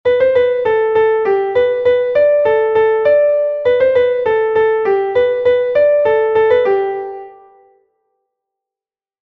Melodie: Schnadahüpfel-Meldoie, um 1830